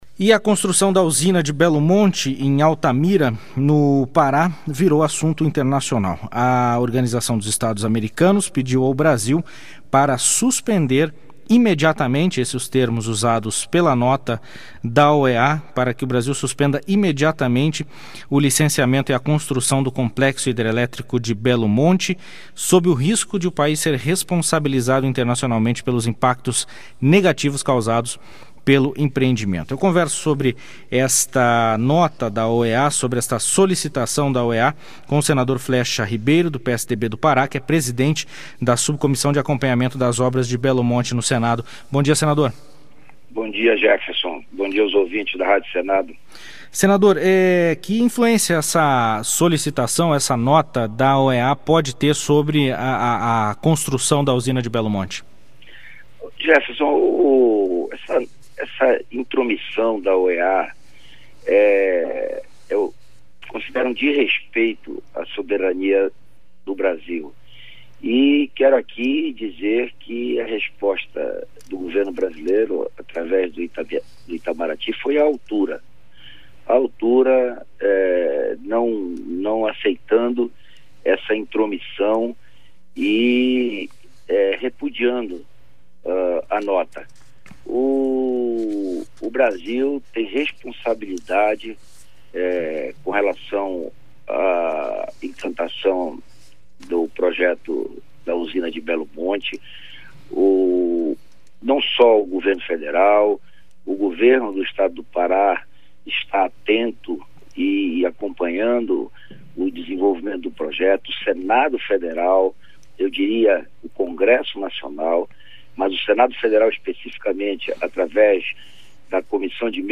Entrevista com o senador Flexa Ribeiro (PSDB-PA).